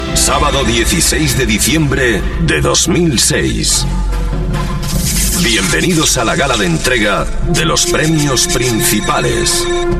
Careta del programa.